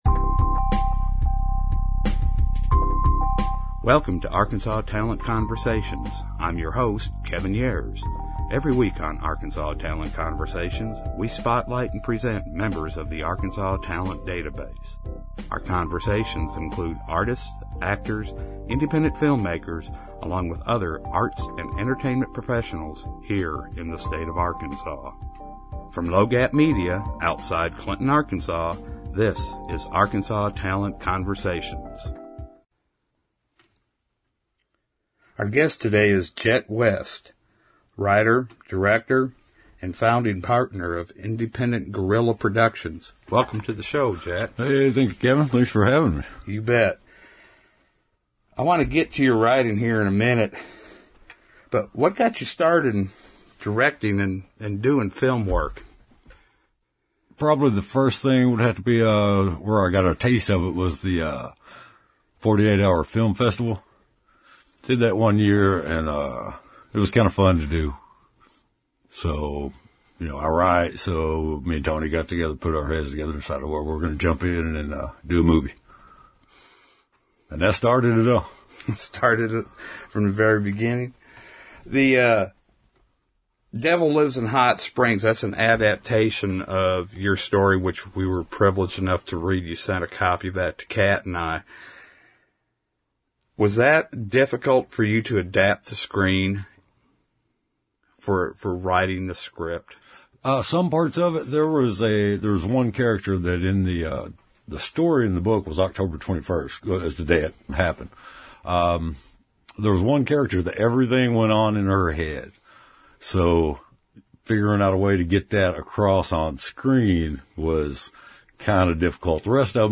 Arkansas Talent Conversations is a weekly podcast that interviews Arts and Entertainment Professionals for perspectives into today's art, film and entertainment scene.